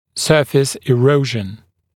[‘sɜːfɪs ɪ’rəuʒn][‘сё:фис и’роужн]эрозия поверхности